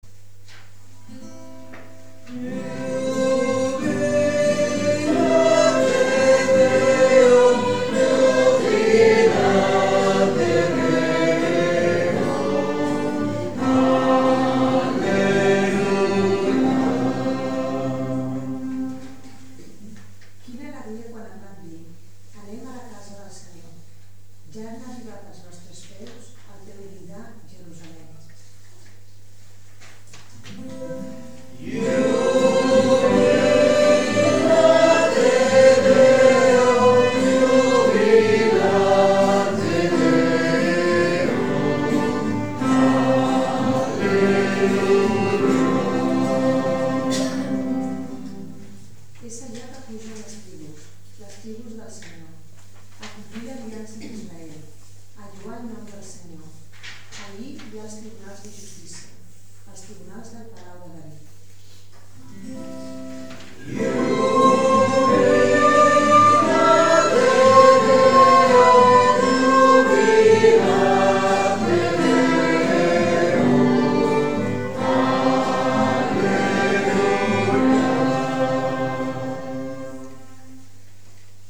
Pregària de Taizé
Capella de les Concepcionistes de Sant Josep - Diumenge 24 de novembre de 2013